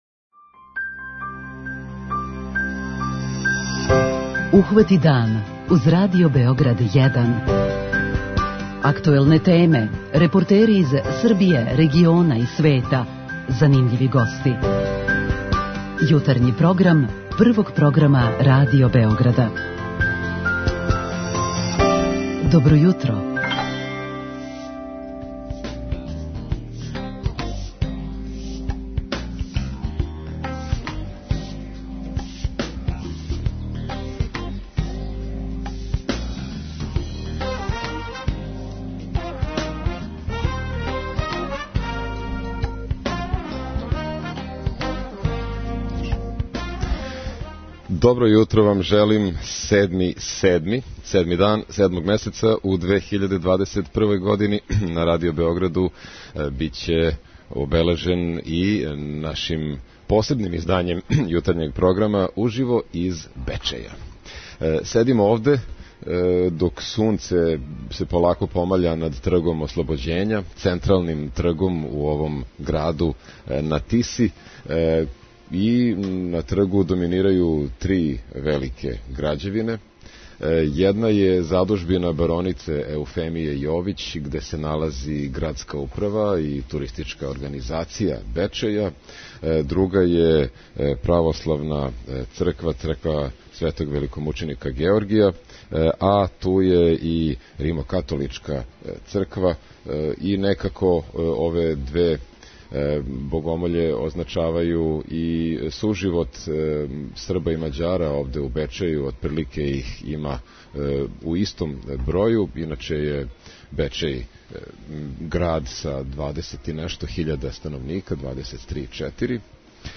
Ово издање јутарњег програма емитујемо уживо из града на Тиси. Бечеј је недавно проглашен за град који најбоље комуницира с грађанима кроз такозвану 'Грађанску столицу' па смо дошли да проверимо како то изгледа.
Поред њих, у нашем импровизованом студију на централном градском тргу у Бечеју угостићемо саговорнике из света културе и спорта, омладинске активисте и туристичке посленике, али и једног локалног винара.